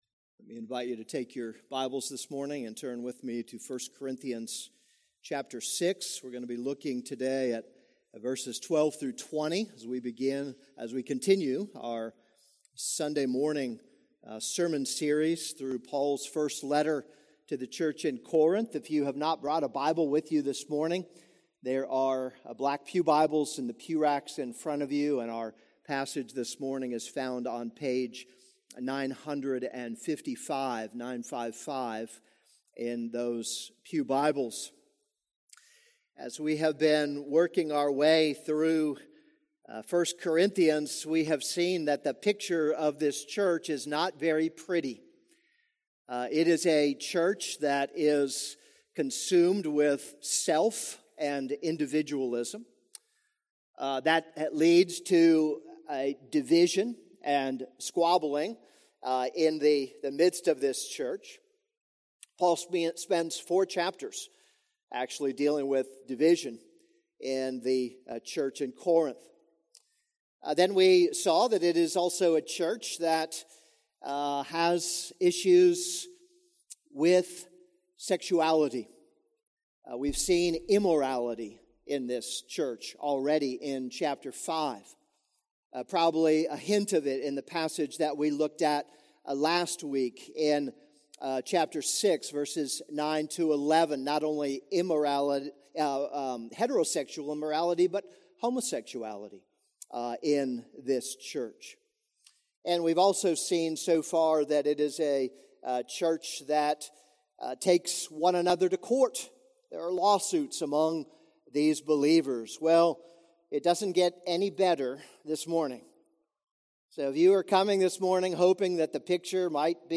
This is a sermon on 1 Corinthians 6:12-20.